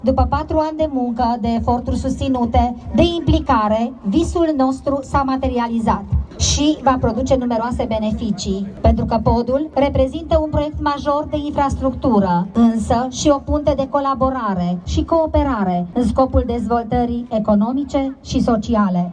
Podul peste Someș, care face legătura între județele Satu Mare şi Maramureș, a fost inaugurat joi, 14 aprilie, la Seini, în prezența ministrului Transporturilor, Sorin Grindeanu.
Primarul orașului Seini, Gabriela Tulbure, vorbește despre importanța realizării acestui pod: